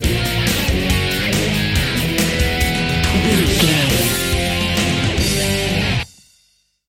Aeolian/Minor
drums
electric guitar
bass guitar
Sports Rock
hard rock
lead guitar
aggressive
energetic
intense
powerful
nu metal
alternative metal